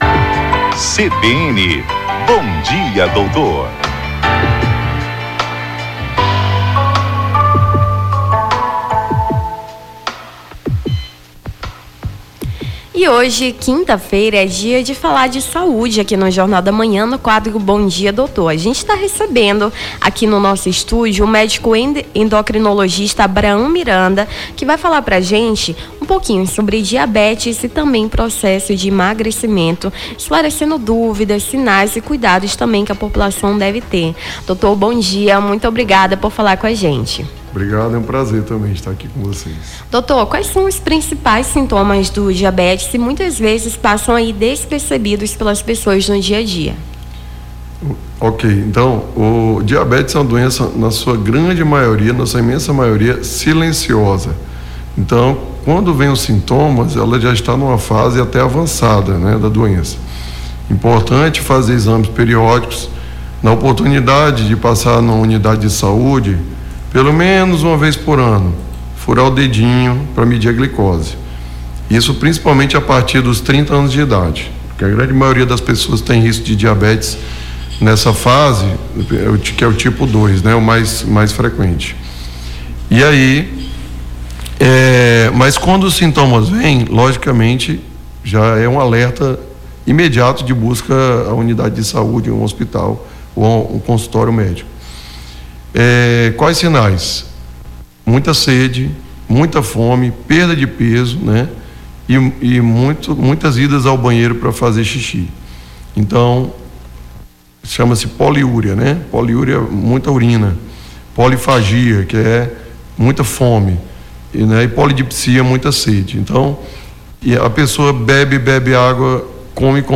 a apresentadora
conversou com o médico